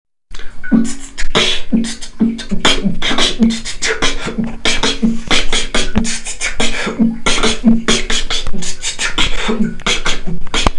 Форум российского битбокс портала » Реорганизация форума - РЕСТАВРАЦИЯ » Выкладываем видео / аудио с битбоксом » Мои биты (Сюда быду выкладывать все известные мне биты)
в этом бите вся проблема в нехватке воздуха, поэтому надо постараться во время хетов выдыхать побольше воздуха